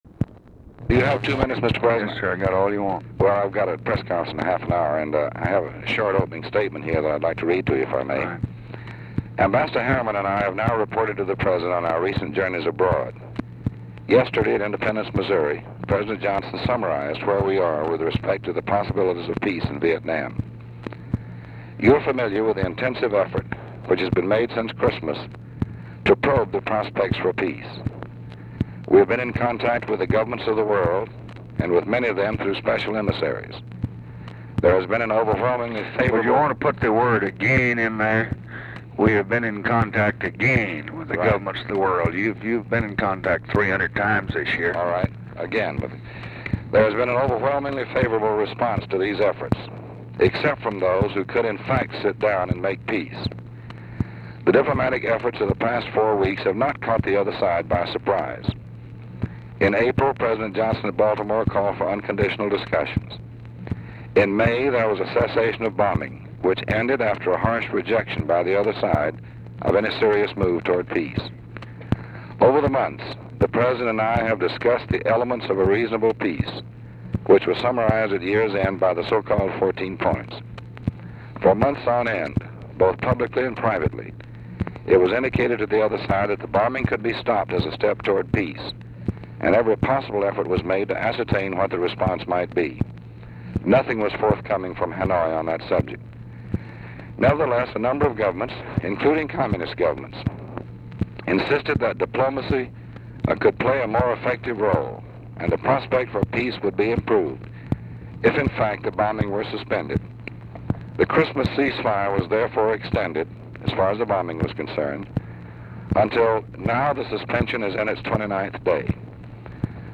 Conversation with DEAN RUSK, January 21, 1966
Secret White House Tapes